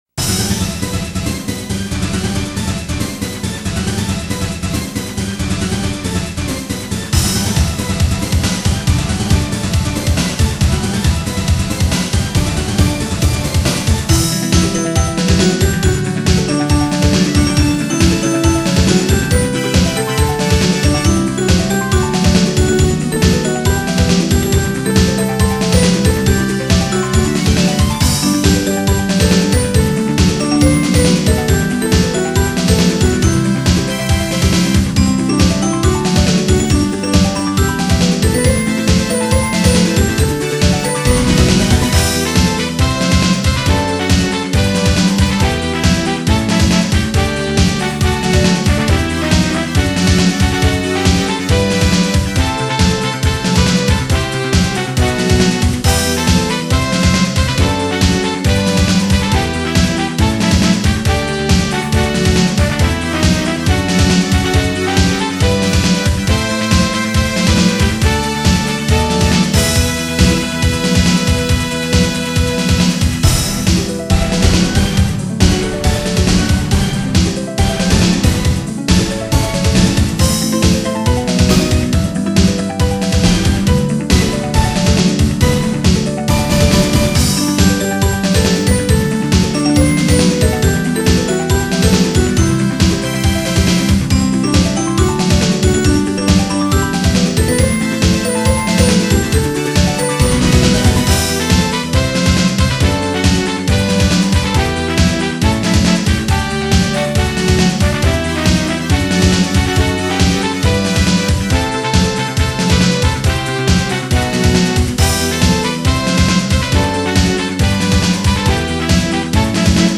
この原曲は8分くらいある長い曲で、大きく前半と後半で曲調が変わりますが、今回はその後半の部分です。
これらの曲は、全てドリームキャストを使って作りました。